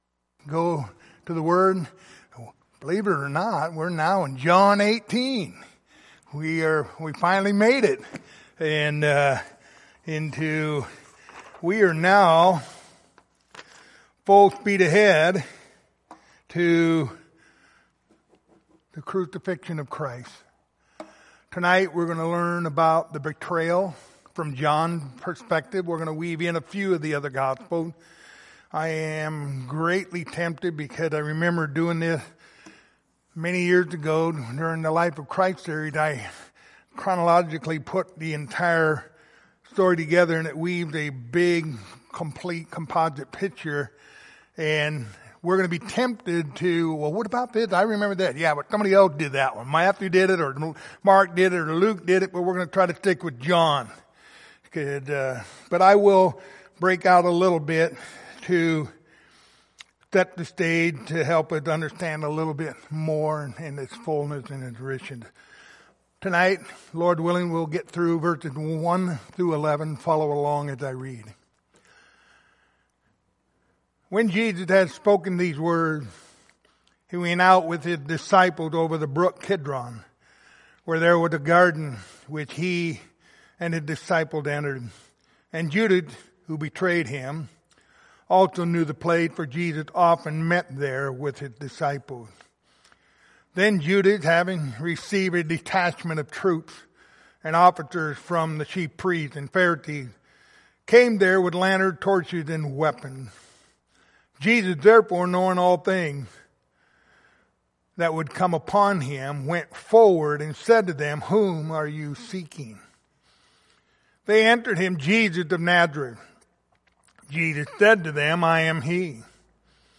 Passage: John 18:1-11 Service Type: Wednesday Evening Topics